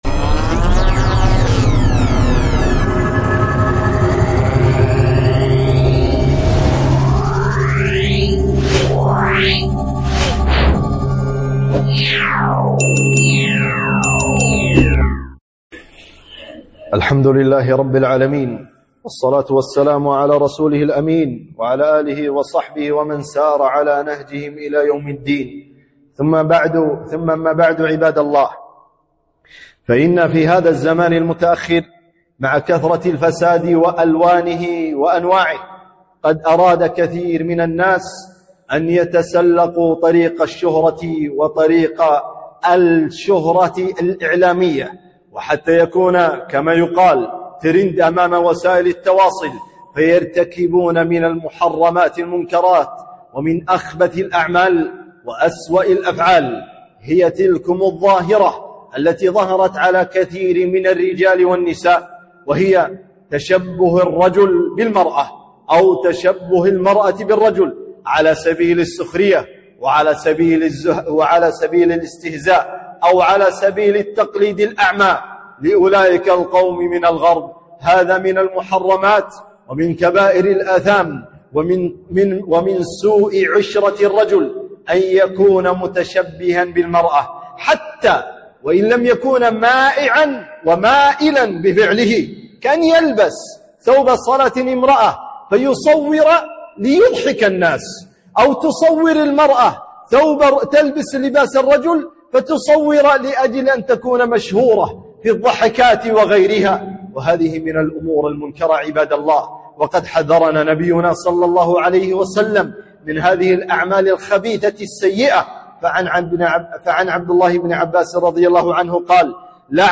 خطبة - خطر تشبه الرجال النساء والعكس